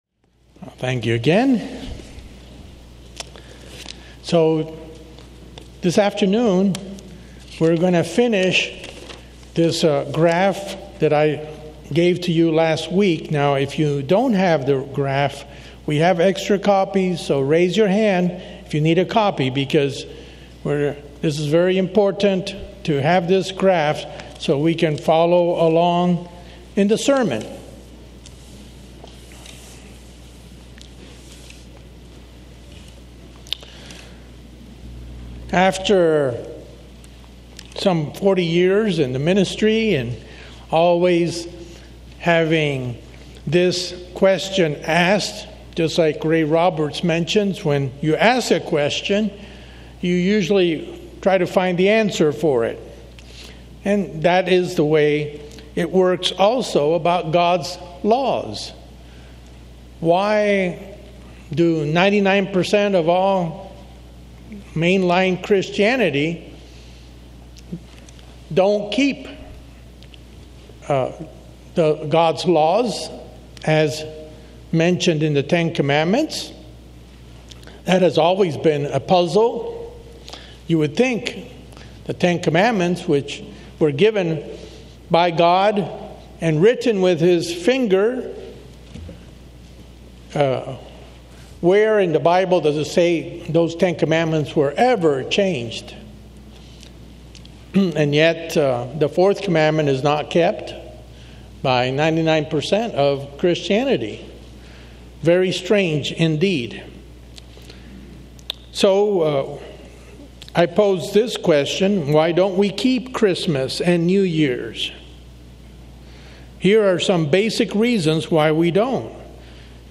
This conclusion of a two-part sermon explains the basic reasons why certain laws were made by God and why they are not to be abolished, as some have declared.